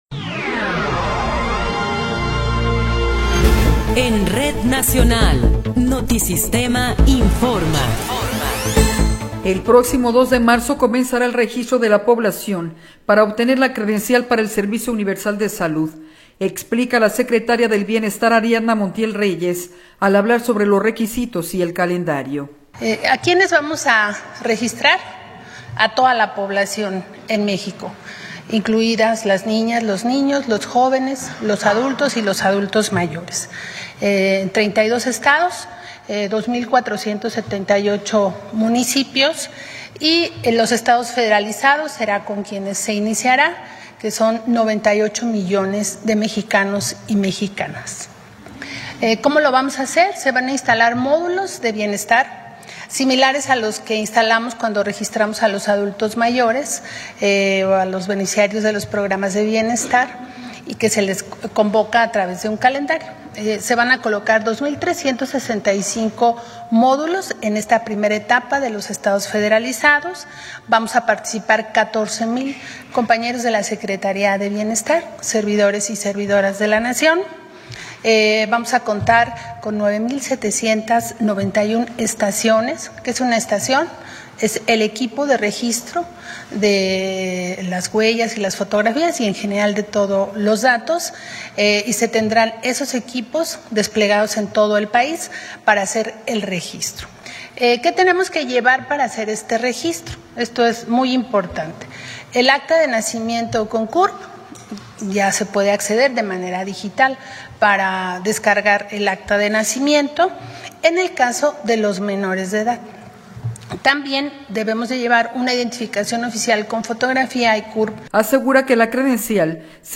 Noticiero 17 hrs. – 1 de Febrero de 2026